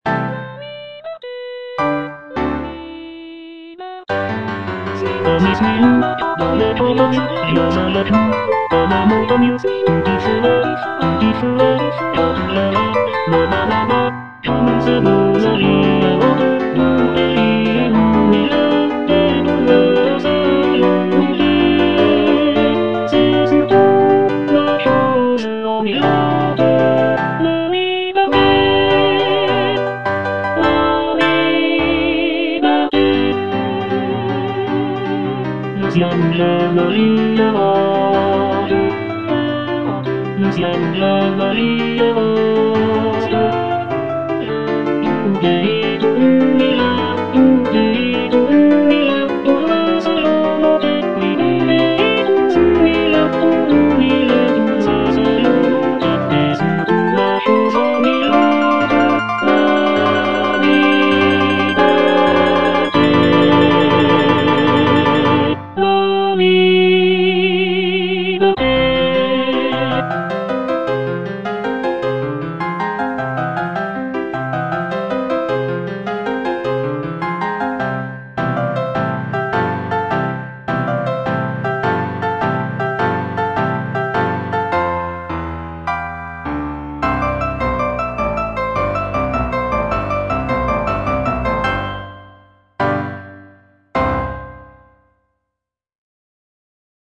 G. BIZET - CHOIRS FROM "CARMEN" Suis-nous à travers la campagne (tenor II) (Emphasised voice and other voices) Ads stop: auto-stop